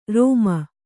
♪ rōma